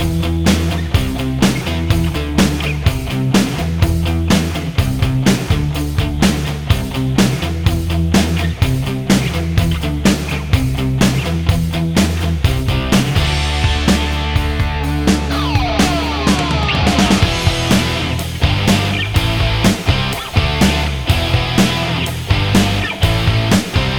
no Backing Vocals Soft Rock 4:13 Buy £1.50